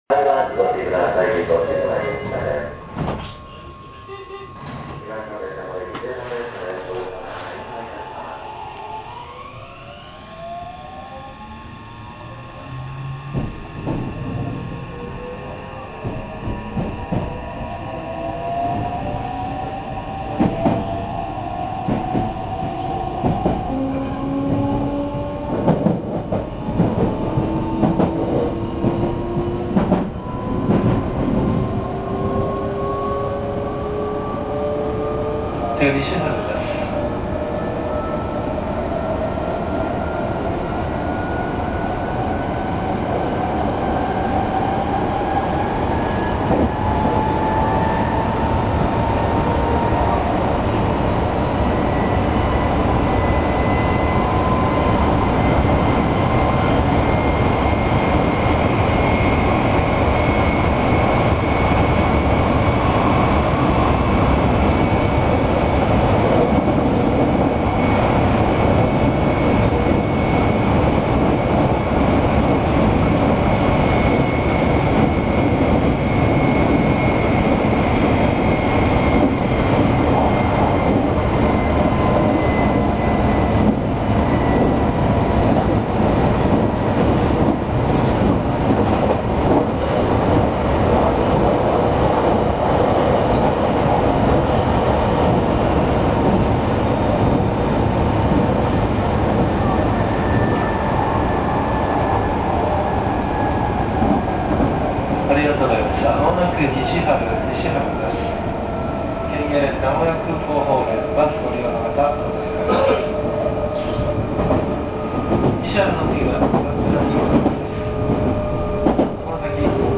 ・3150系・3300系(三菱IGBT)走行音
【名古屋本線】上小田井〜西春（2分28秒：1.13MB）
3150_Kamiotai-Nishiharu.mp3